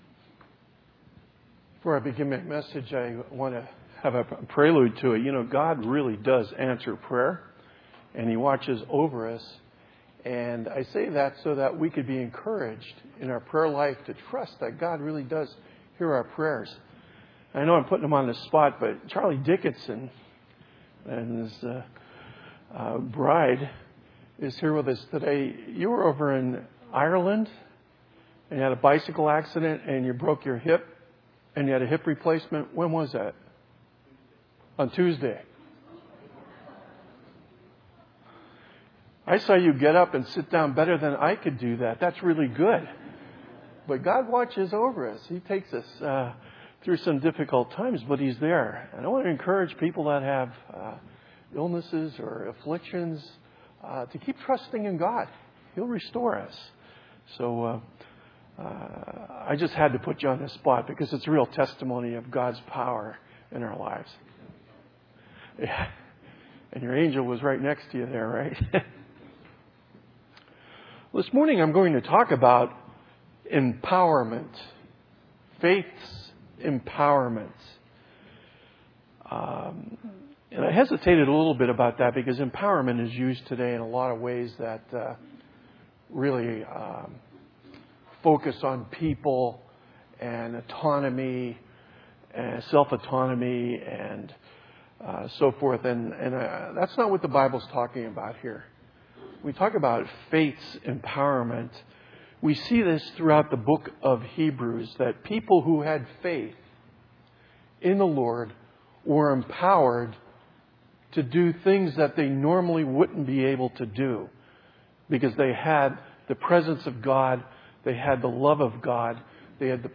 A Collection of 2016 Sermons from Windsor Baptist Chruch